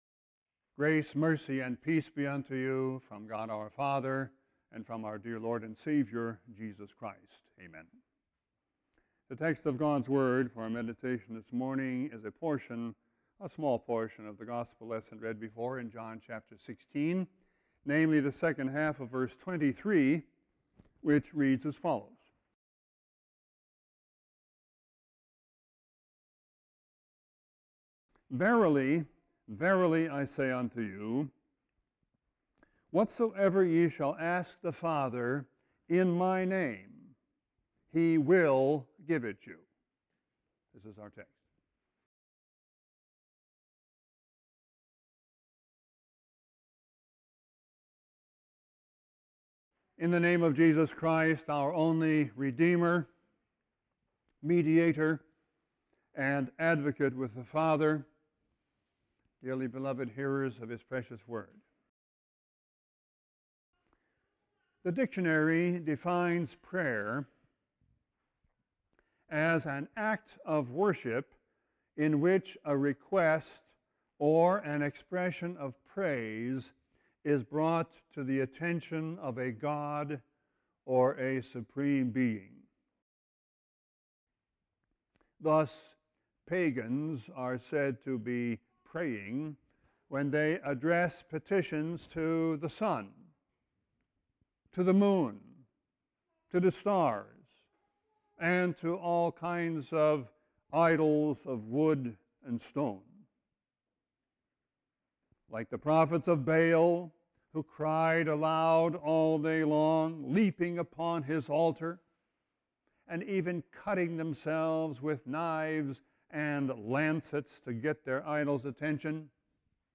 Sermon 5-5-13.mp3